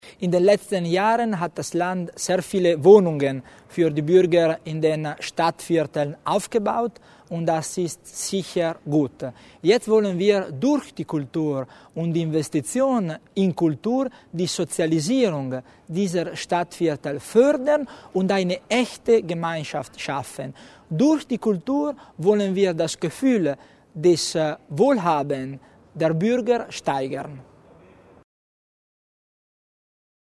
Landesrat Tommasini zur Bedeutung des Projektes